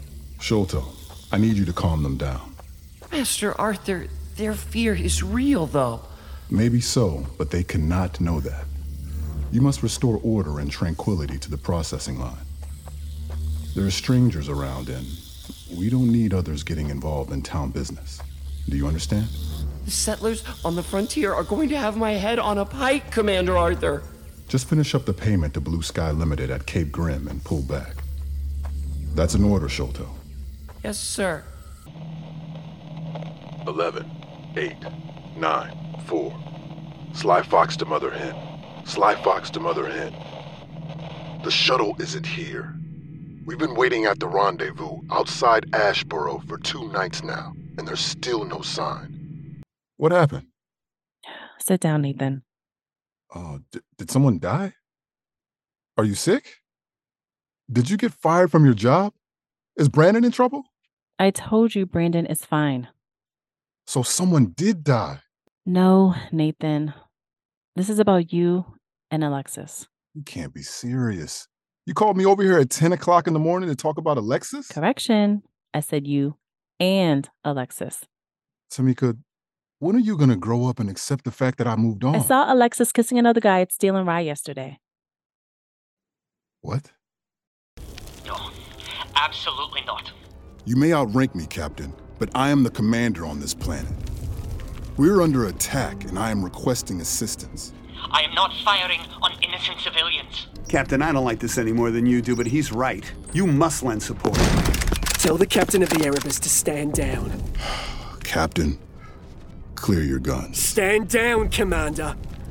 Audio Drama Reel